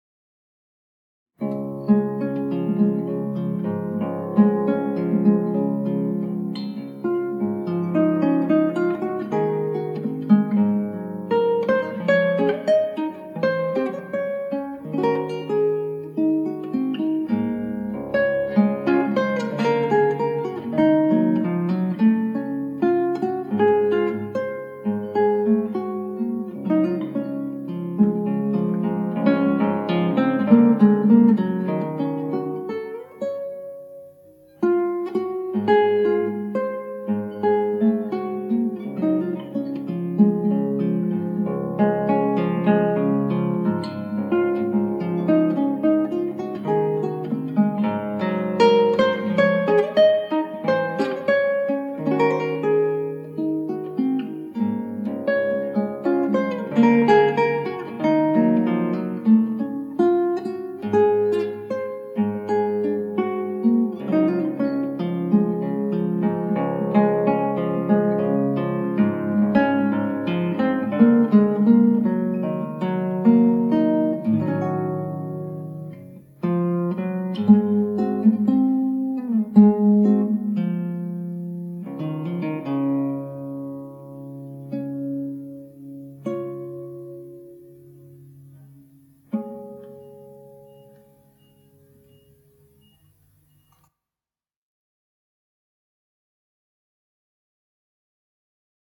クラシックギター　ストリーミング　コンサート
やっぱテーマだけ録音デス